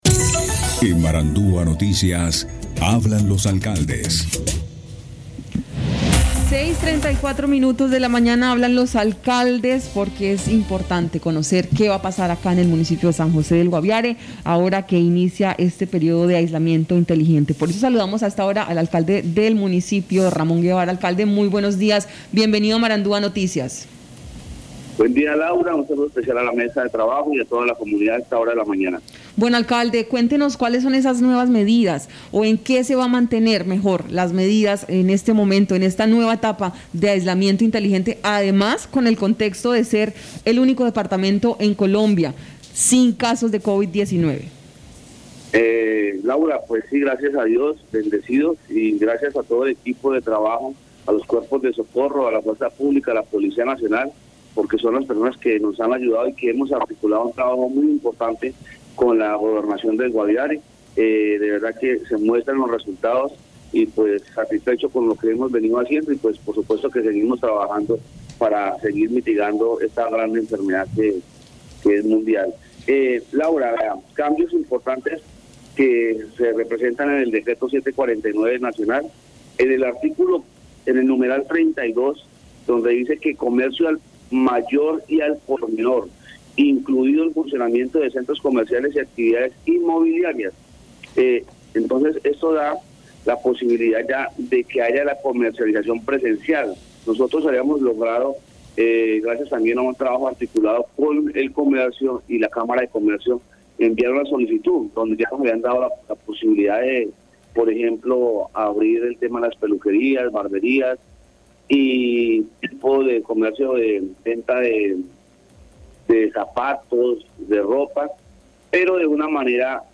Escuche a Ramón Guevara, alcalde de San José del Guaviare.